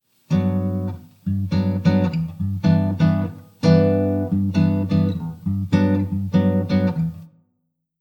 I’ve also added a little Reverb effect (Vocal 2 setting with the “wet” turned down a bit)
I then applied a little bit of Eq with the “Equalization” effect to reduce the bass and give it a bit of boost at around 4000 Hz (4 kHz). The original sounded a bit boomy through my headphones which is why I reduced the bass, and after noise reduction it sounded a bit dull, which is why I added a little boost to the high frequencies.